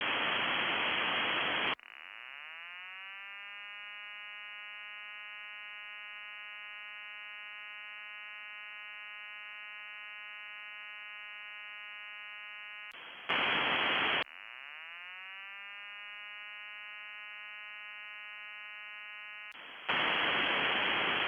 RUIDO CON AMPLIFICADOR – – Técnico – Foro de URE
El ruido que escucho es como cuando se inicia la turbina, pero se me cuela, no es tan fuerte como para que se meta directo por la capsula, yo creo que se me mete por el cableado (ya le he puesto 2 ferritas).
Ruido-ventilador.m4a
En el se oye un mosquito trompetero gigante 🙂
Hola, parece que es una interferencia activa del motor, lo mismo hay un problema en el, seria cuestion de hechar un vistazo en el esquema y ver que tenemos, lo mismo ya trae esos filtros y estos han fallado, bien el motor esta tocado en el sentido de que tiene una averia, ya digo, antes de nada evaluar que esta ocurriendo. si puedes decirnos la marca y modelo exacto mejor, para asi buscar info